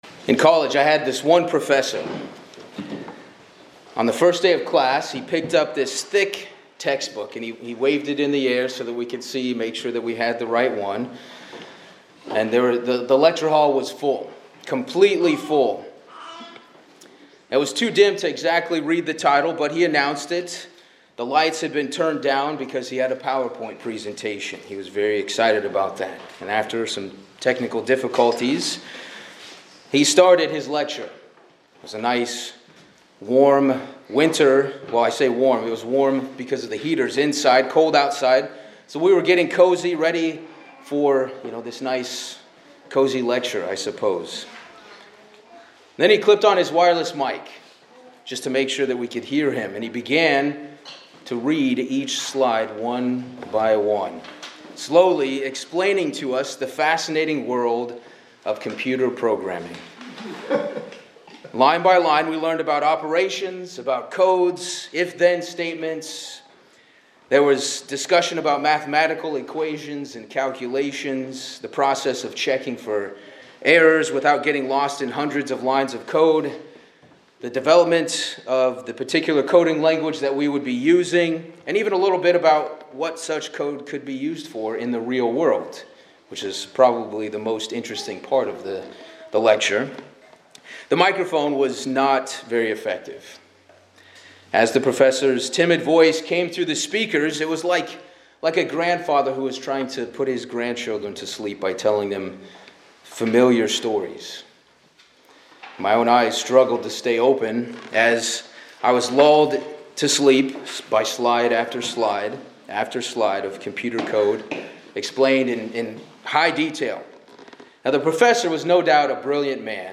This sermon encourages listeners to consider what kind of story their lives are telling, highlighting that God desires positive change and is always ready to forgive those who repent. Ultimately, the message calls for living a life of active growth, openness to correction, and lasting change, so that the “story” recorded about each person is one of overcoming and spiritual maturity.
Given in Hartford, CT